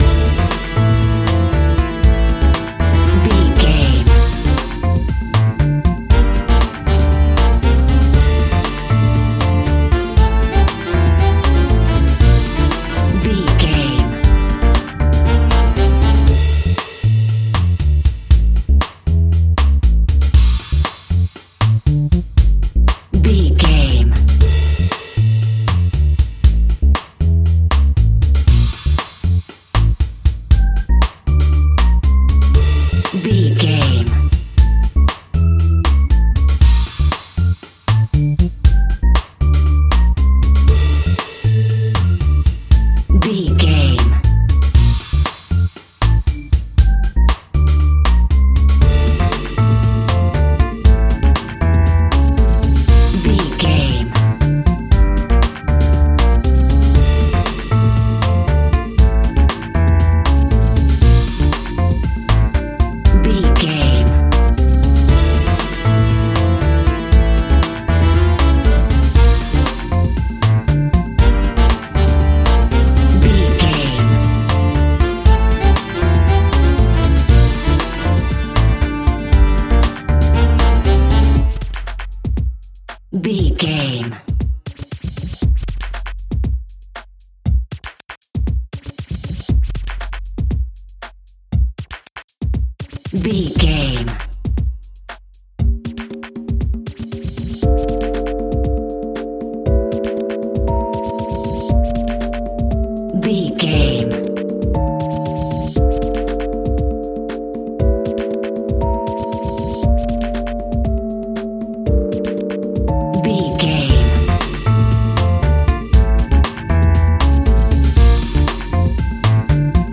Aeolian/Minor
Funk
groove
salsa
energetic
electric guitar
bass guitar
drums
hammond organ
fender rhodes
percussion